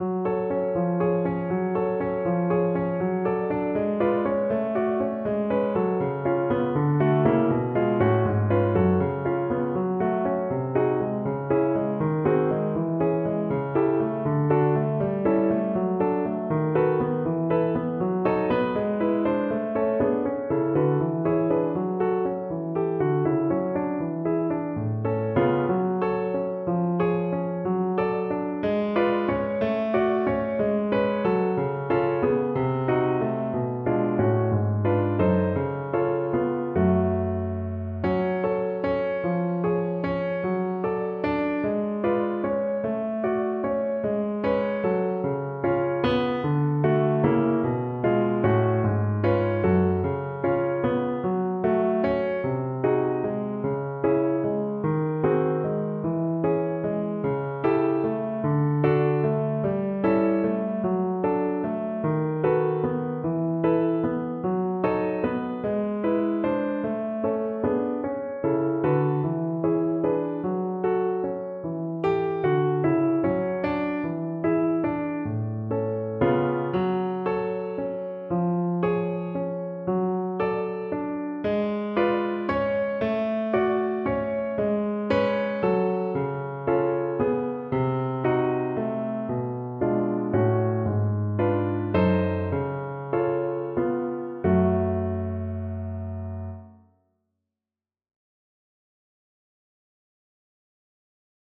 Violin version
6/8 (View more 6/8 Music)
Violin  (View more Easy Violin Music)
Classical (View more Classical Violin Music)